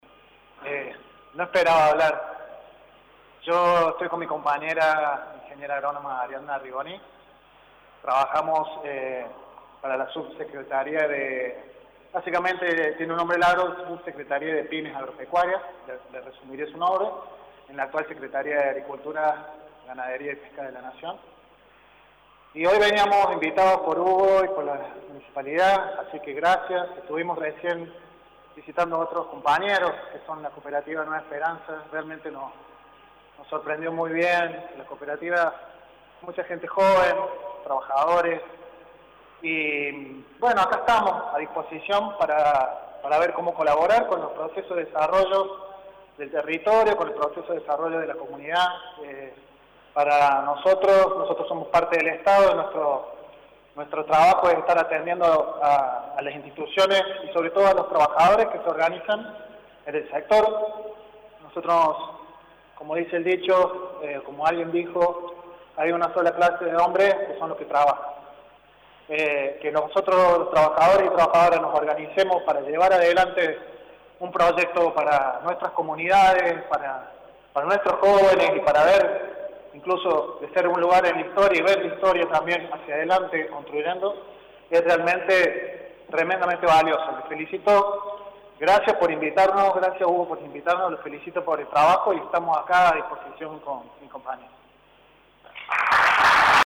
Además, se refirió a la inaguruación el el Subsecretario de Pequeñas y Medianas Empresas del Ministerio de Agricultura de la Nación, Sr. Horacio Britos: